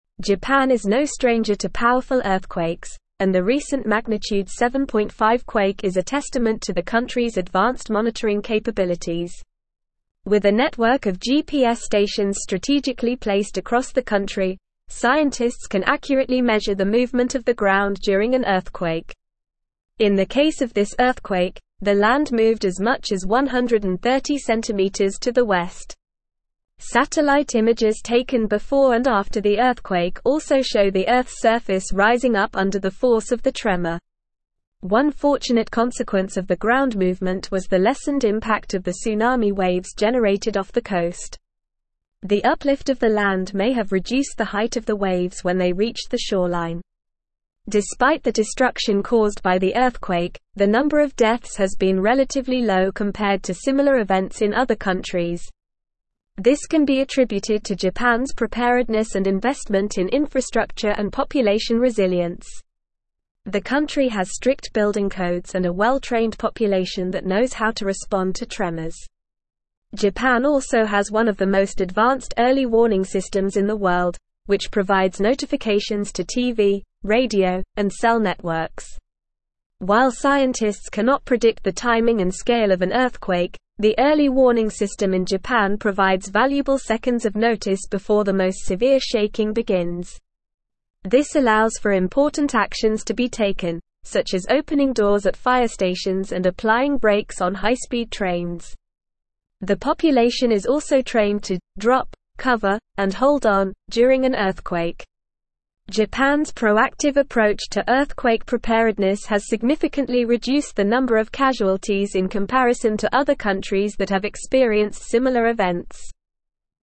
Normal
English-Newsroom-Advanced-NORMAL-Reading-Japans-Earthquake-Preparedness-Low-Death-Toll-High-Resilience.mp3